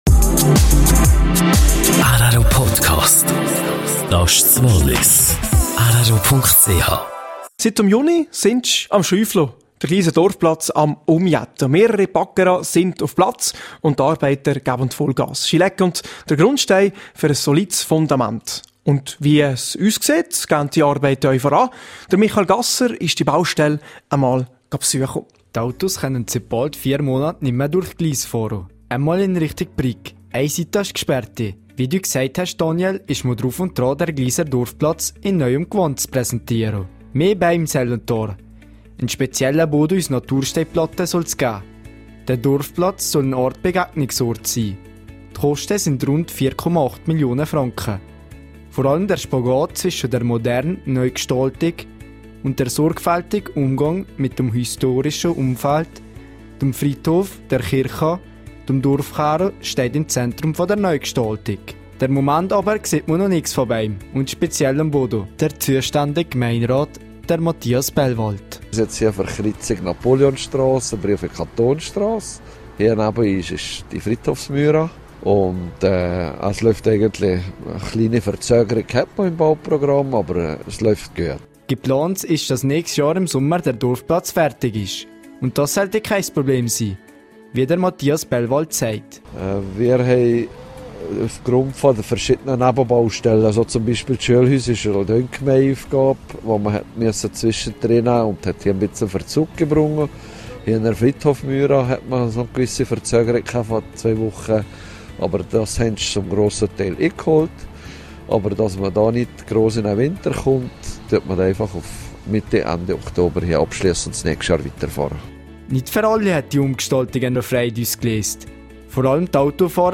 Mathias Bellwald zuständiger Gemeinderat über die Neugestaltung des Gliser Dorfplatzes.